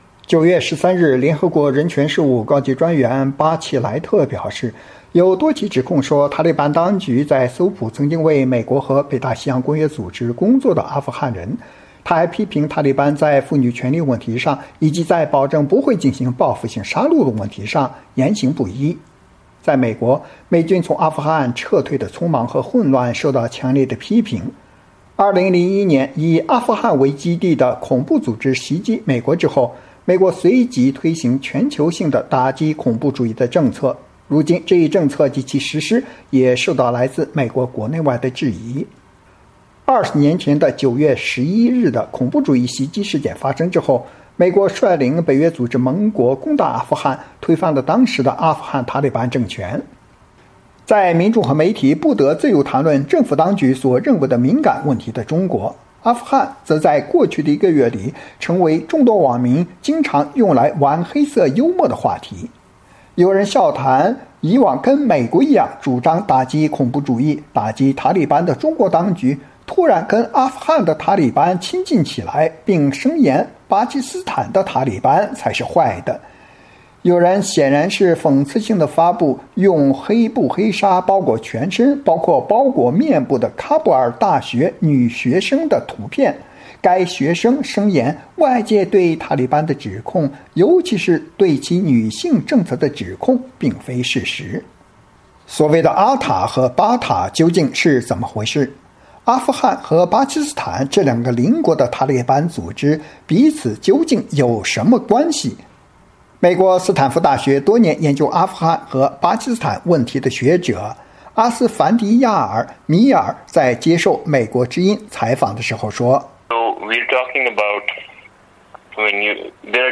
美国之音专访